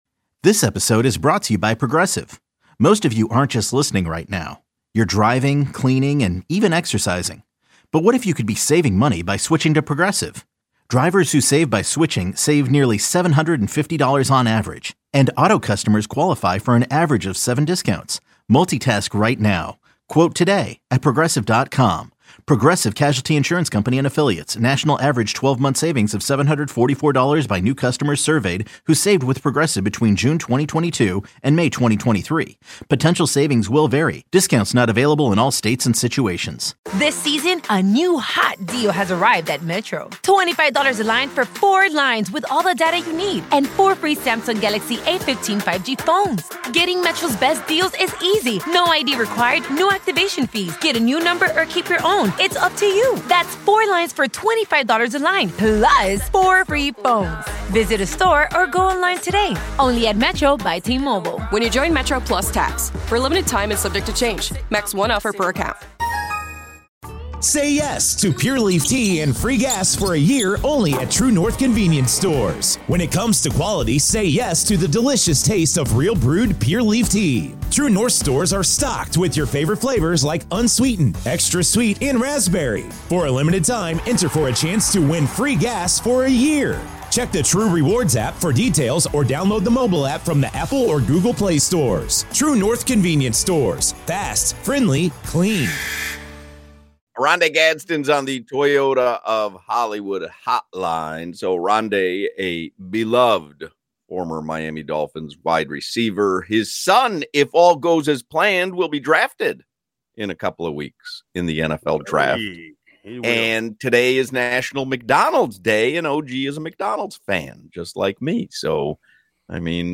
Oronde Gadsden joins the show live from a McDonald’s Drive Thru to celebrate National McDonald’s day.